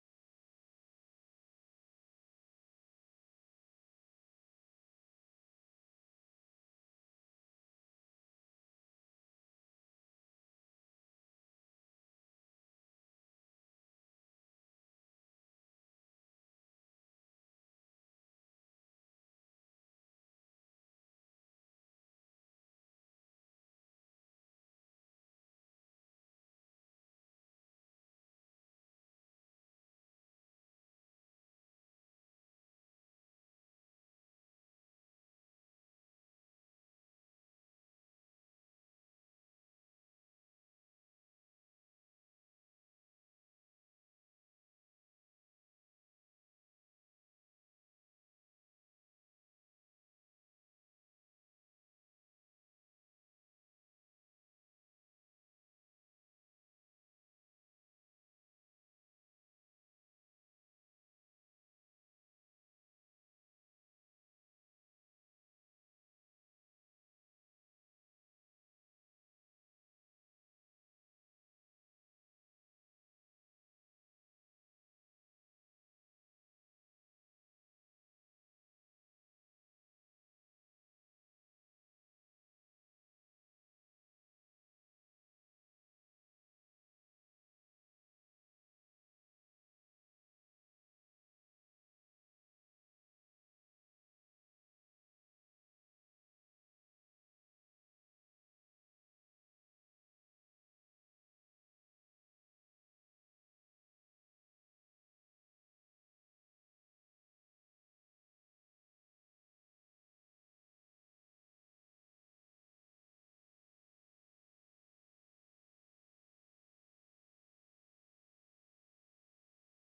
Responsibility – Gifts Sermon
Responsibly-Gifts-Sermon-Audio-CD.mp3